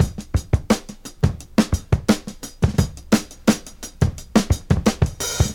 • 115 Bpm Drum Loop Sample A Key.wav
Free drum groove - kick tuned to the A note. Loudest frequency: 1555Hz
115-bpm-drum-loop-sample-a-key-xhq.wav